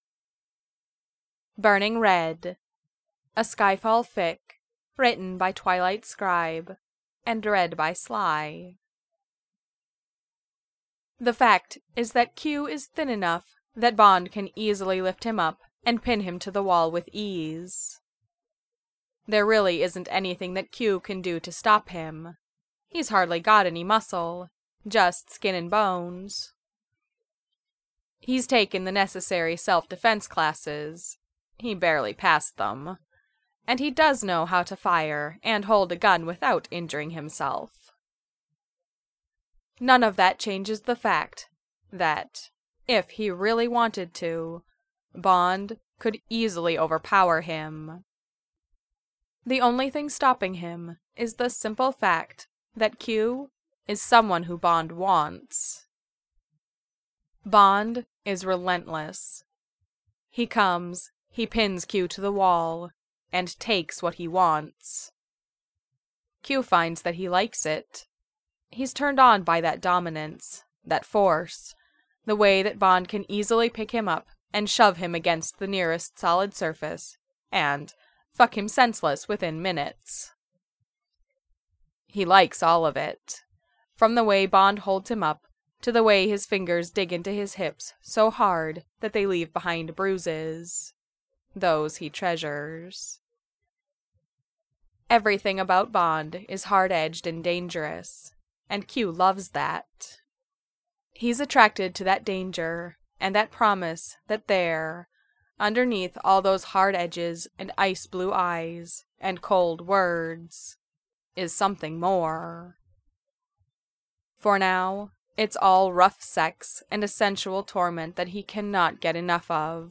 MP3 (2.46 MBs) |-|-| Audiobook (1.58 MBs) (Right click and "Save As")